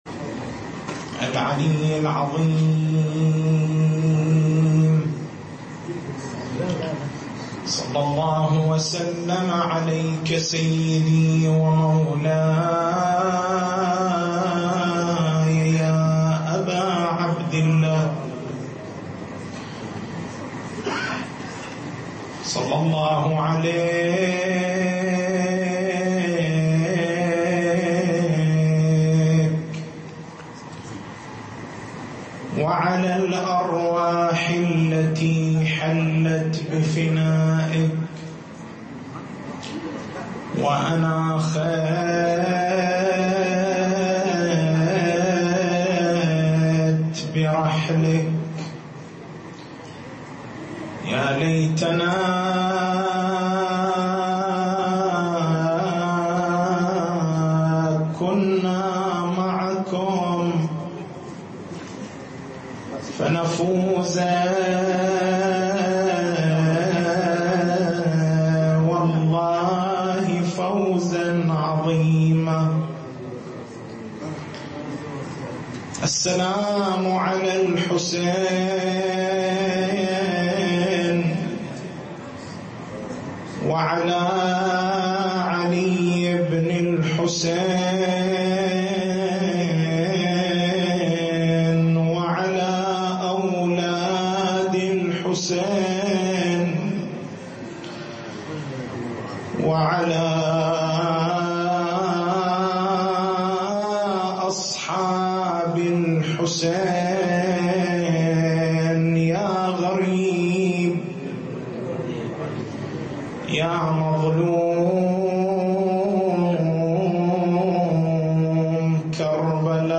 تاريخ المحاضرة: 23/01/1437 محور البحث: معنى قول سيّد الشهداء (ع) في حقّه ولده علي الأكبر (ع): ((دعنه؛ فإنّه ممسوسٌ في ذات الله)).